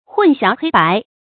混淆黑白 注音： ㄏㄨㄣˋ ㄒㄧㄠˊ ㄏㄟ ㄅㄞˊ 讀音讀法： 意思解釋： 混淆：混雜不清；使界限模糊。